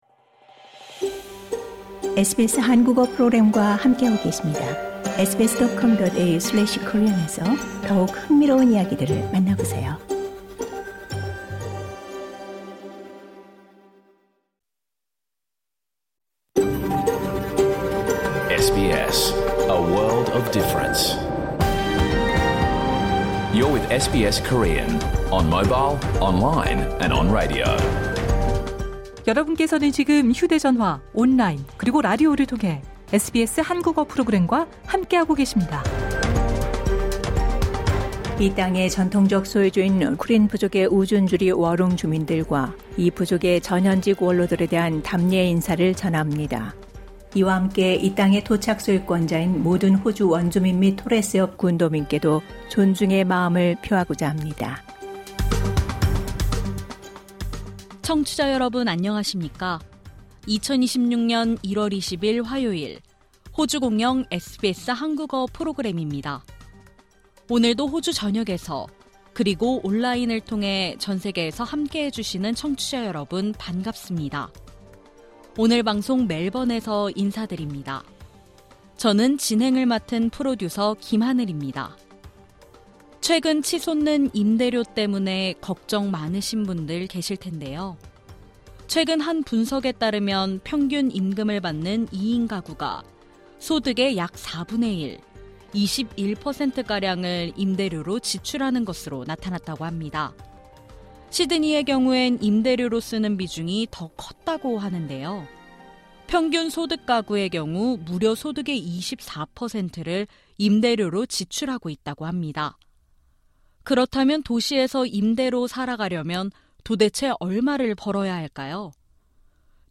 2026년 1월 20일 화요일에 방송된 SBS 한국어 프로그램 전체를 들으실 수 있습니다.